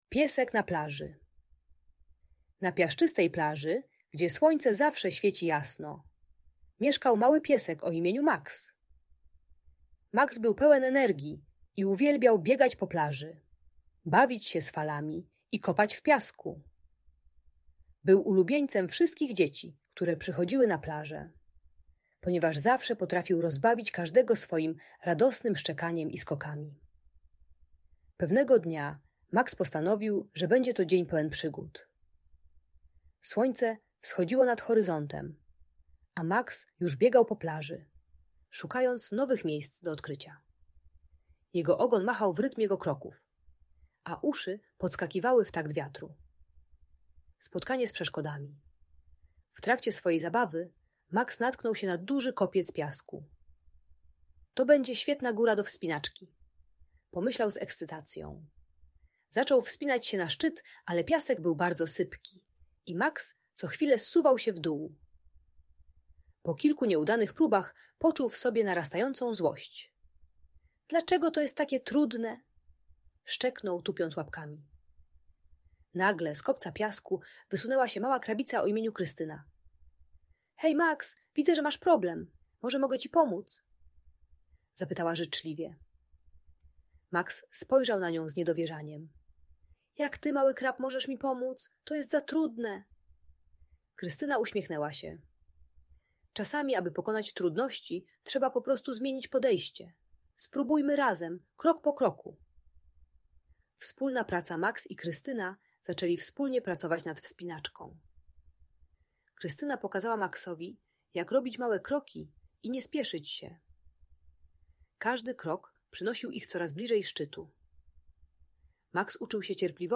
Przygody pieska Maxa na plaży - Audiobajka